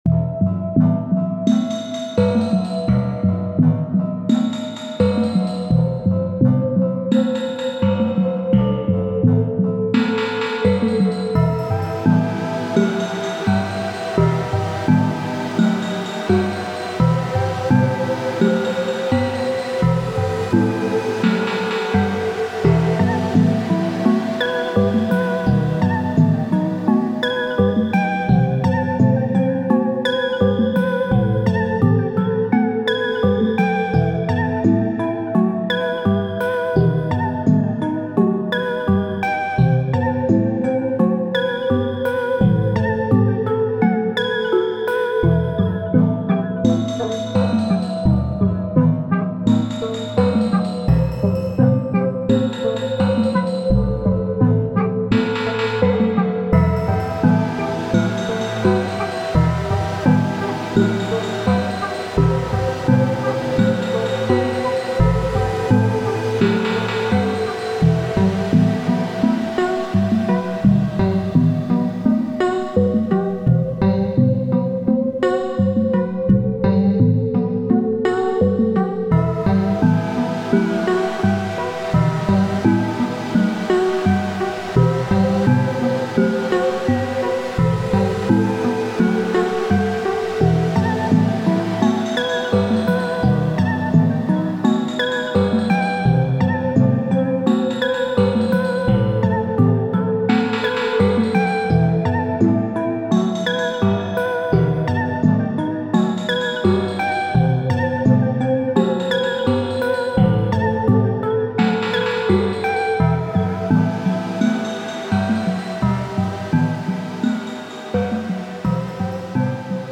じっとりとした暗い雰囲気のサウンドと声や物音に似たシンセサウンドを組み合わせている。
本格派ホラーというよりは少しマイルド、コミカルな雰囲気が感じられるBGMとなっている。